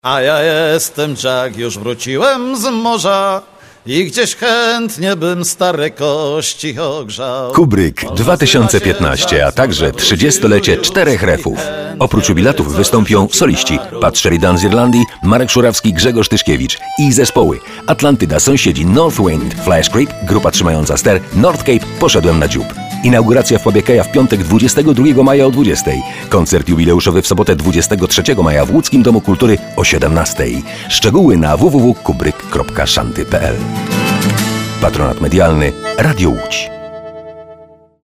spotu promocyjnego